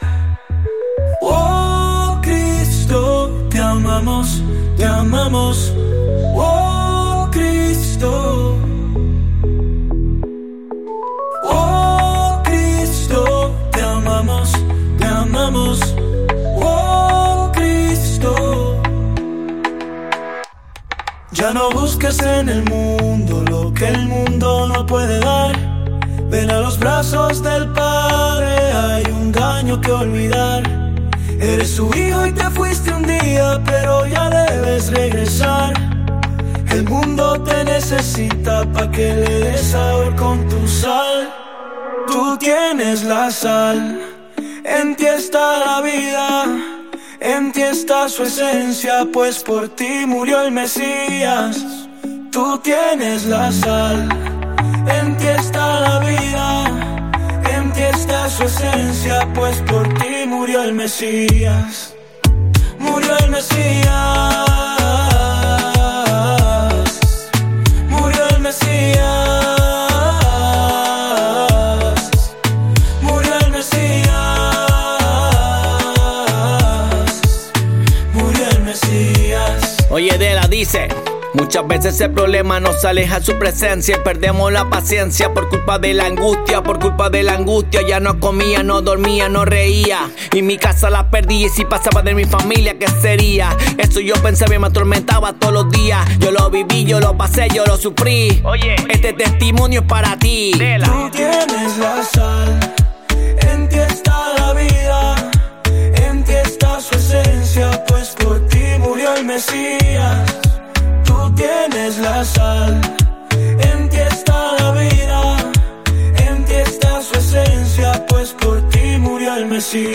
combinando ritmos contagiosos y letras llenas de significado